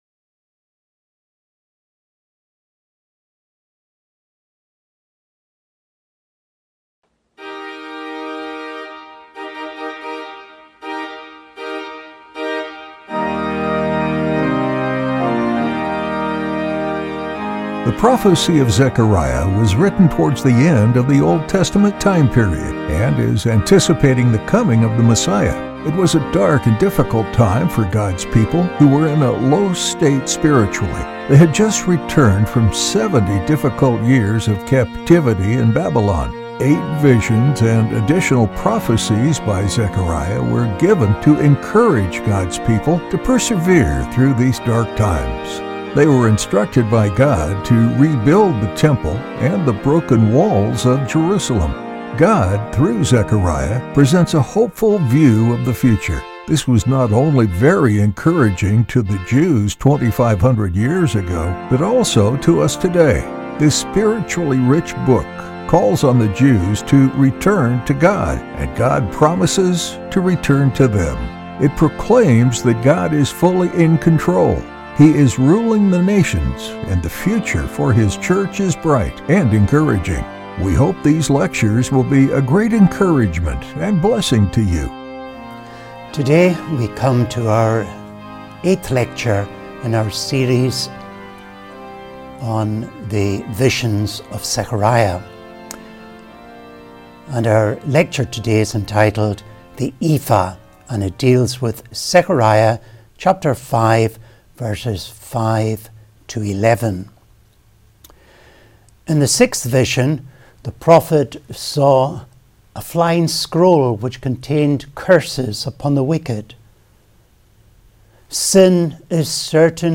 Today we come to our eighth lecture in our series on The Visions of Zechariah, and our lecture today is entitled, “The Ephah,” and it deals with Zechariah, chapter 5, verses 5 to 11. In the sixth vision, the prophet saw a flying scroll which contained curses upon the wicked.